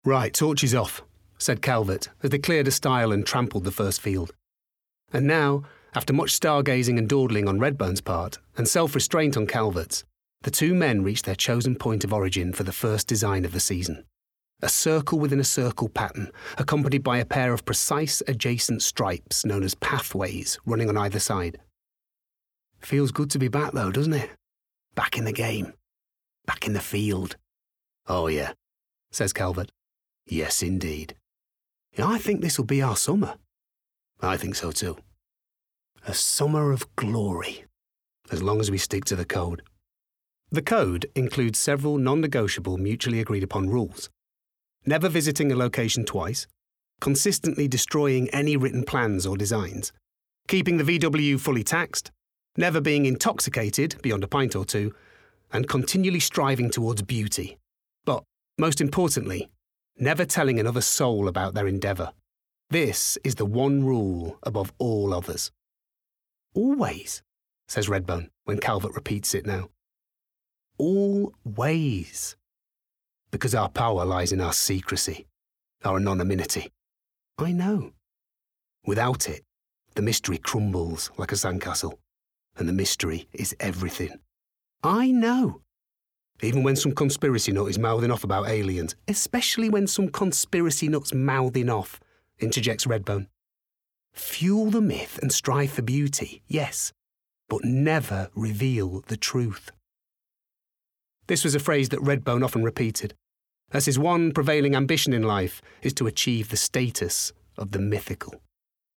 Audio Book/Audio Drama
Northern (English), Yorkshire, Lancashire, Standard English/RP, London/Cockney, American, Mancunian, Irish, Newcastle/Geordie
Actors/Actresses, Corporate/Informative, Natural/Fresh, Smooth/Soft-Sell, Character/Animation, Comedy, Upbeat/Energy, Mature/Sophisticated